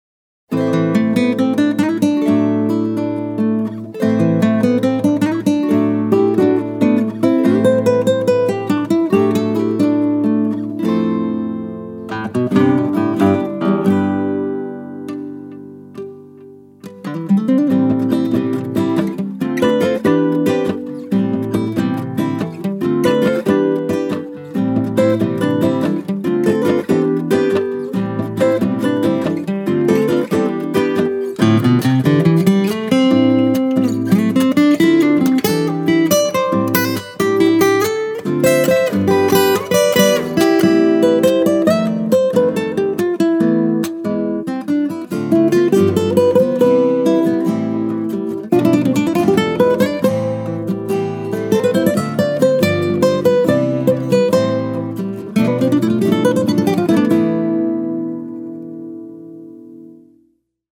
Я решил записать демонстрационную инструментальную пьеску где бы звучали все мои инструменты.
Немного разбавил акустикой с металлическими струнами.